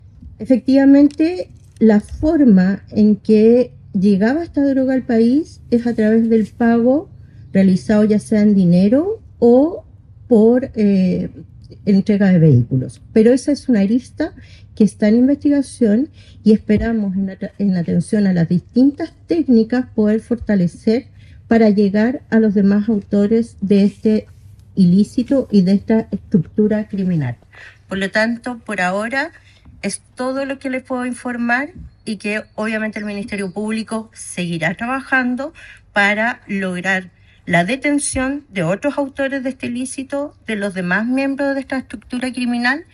Al respecto, la fiscal regional de Tarapacá, Trinidad Steinert, destacó el resultado de la investigación, señalando que “la droga ingresaba al país mediante pagos en dinero o vehículos.